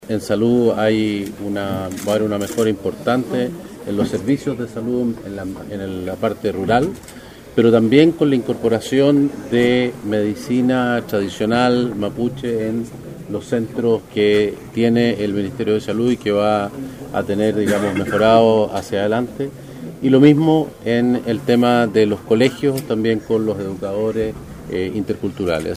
Al término de la reunión, el Ministro de Desarrollo Social contó algunos de los temas conversados.